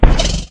sounds / mob / horse / zombie / hit3.mp3